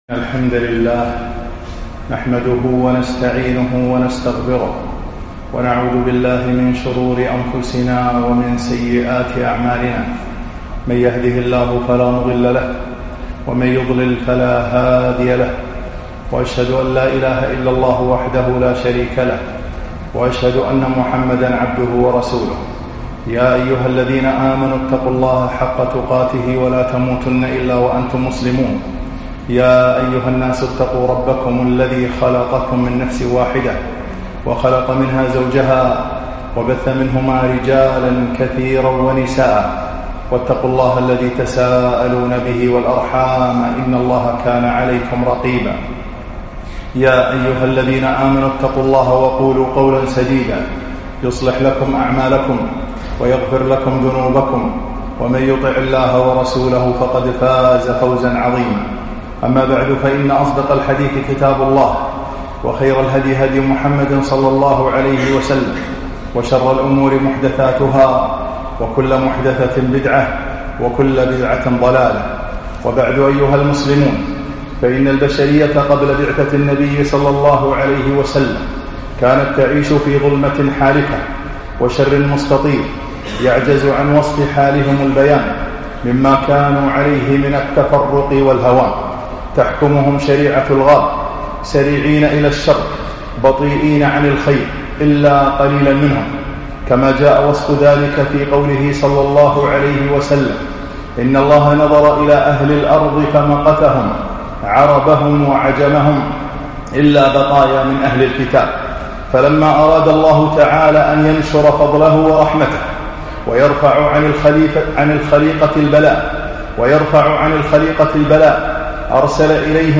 خطب السيرة النبوية 1